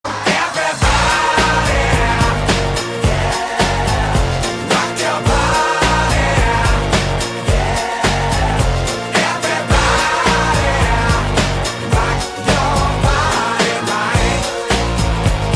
Clip meme de 16 segundos — gratis, en el navegador, sin registro ni descarga obligatoria.
Un clip de audio energético, posiblemente de una canción o sonido motivacional.